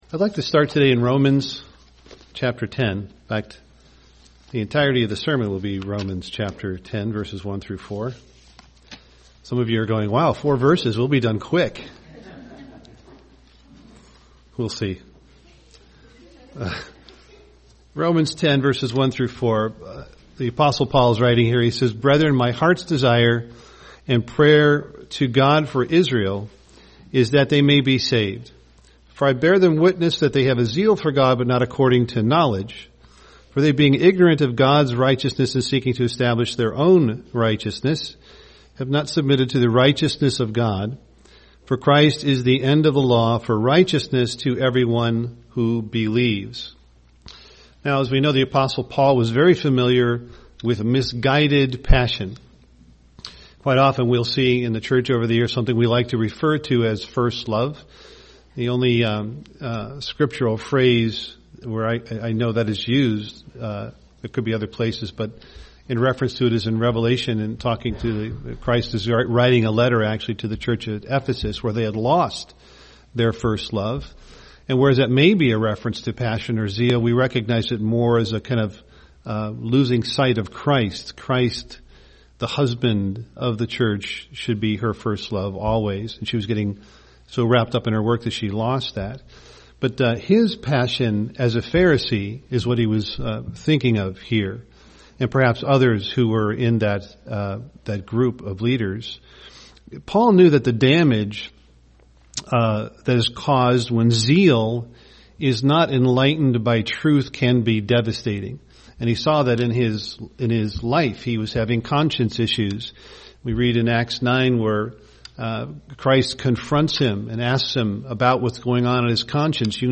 [Based on Romans 10:1-4] UCG Sermon righteousness God's Law Studying the bible?